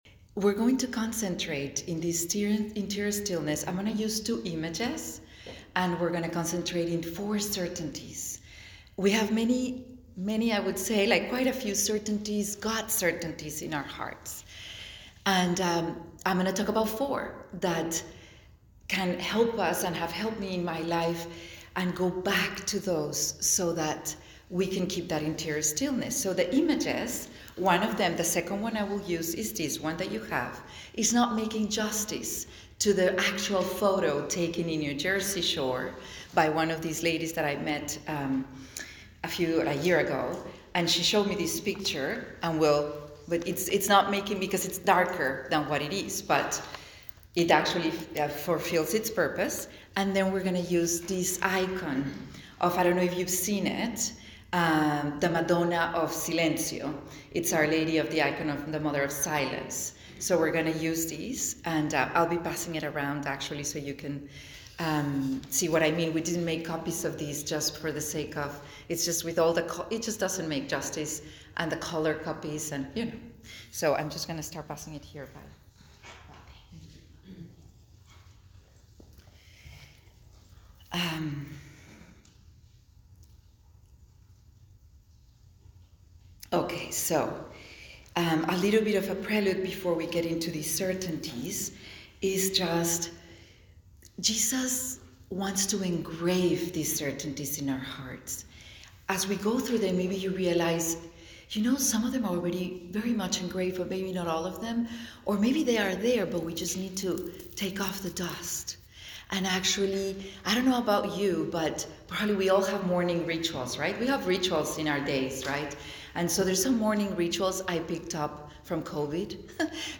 at the morning retreat with Consecrated Women